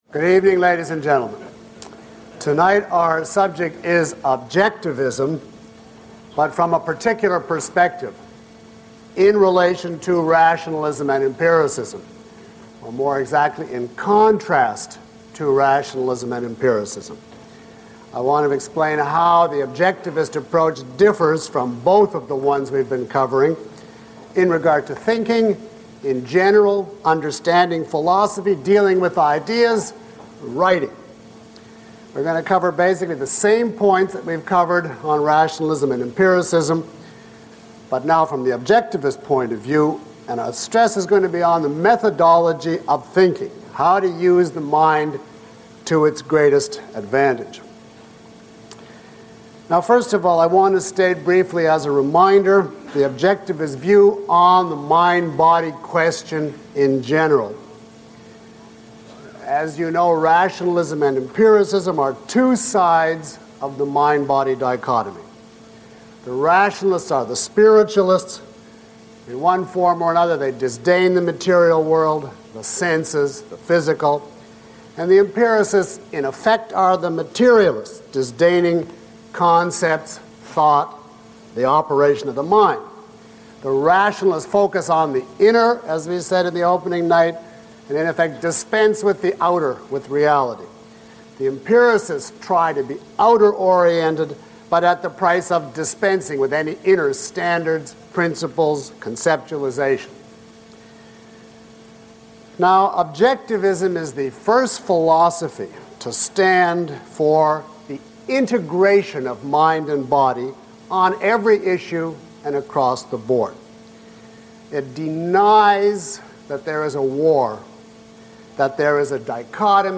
This course features lecture material by Dr. Peikoff as well as exercises and demonstrations from the live audience.